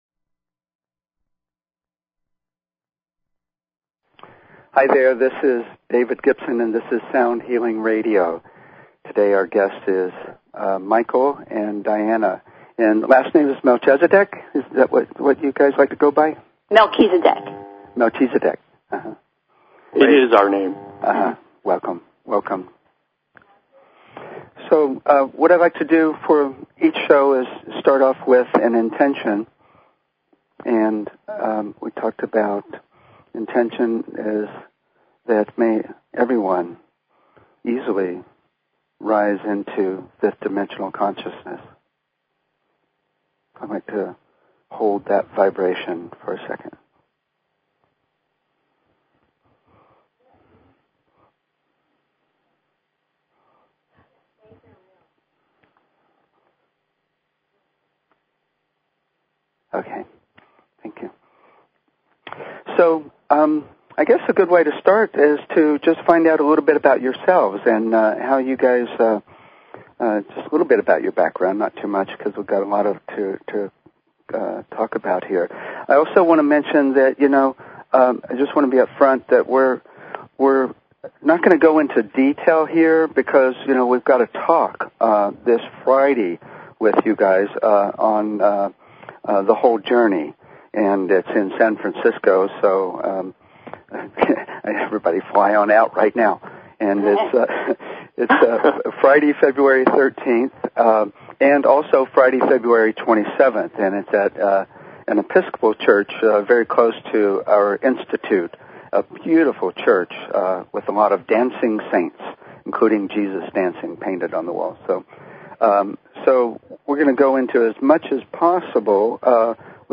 Talk Show Episode, Audio Podcast, Sound_Healing and Courtesy of BBS Radio on , show guests , about , categorized as
Sound Healing Talk Show